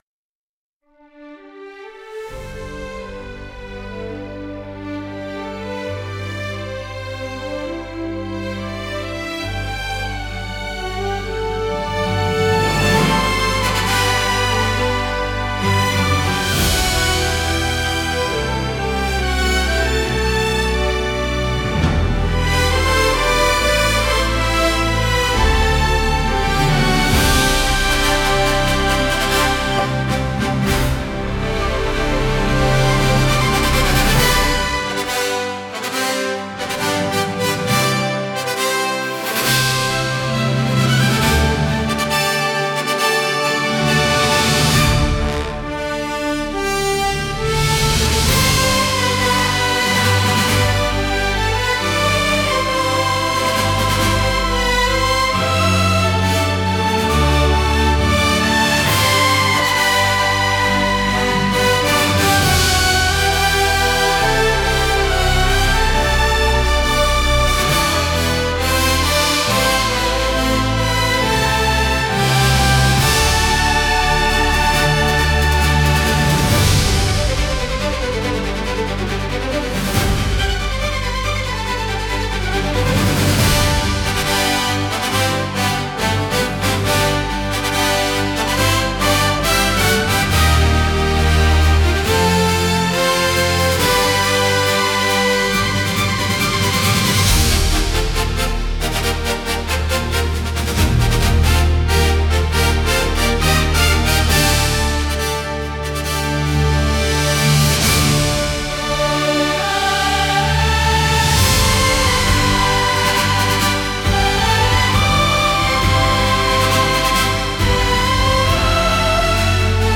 金管楽器の勇壮な旋律が物語の幕開けを告げ、躍動するストリングスが未知の世界を駆け巡る疾走感を演出します。
• ジャンル： シネマティック・オーケストラ / ファンタジー / ゲームサウンド
• 雰囲気： 壮大 / 勇壮 / 希望 / 神秘的 / 感動的
• テンポ（BPM）： 躍動感のあるミドル〜アップテンポ